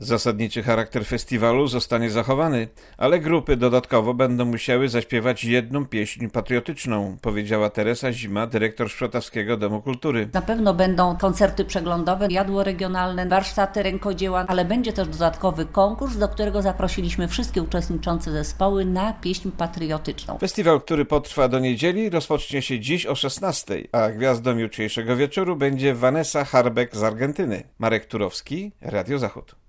Grupy te śpiewają głównie pieśni ludowe i piosenki biesiadne. W tym roku jednak festiwal będzie miał poszerzony program o element związany z obchodami 100. rocznicy odzyskania przez Polskę niepodległości. Relacja